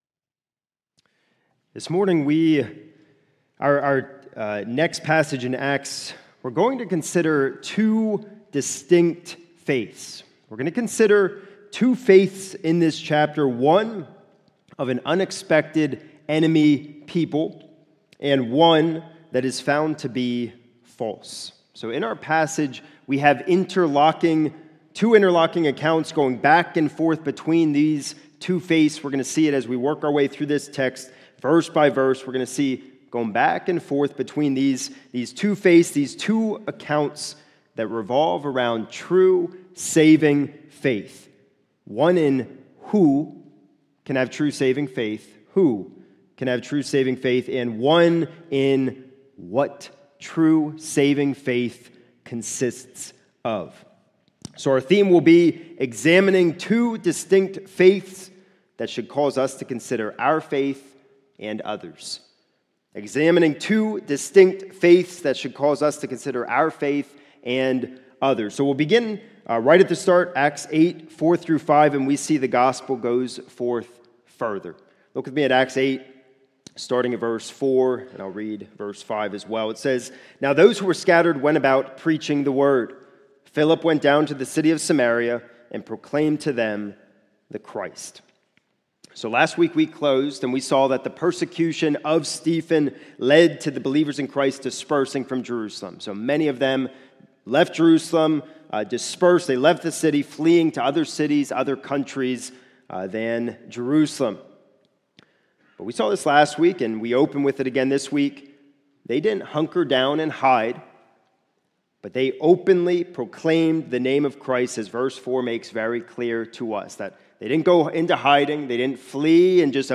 It is taken from Acts 8:4-25. This sermon includes references to: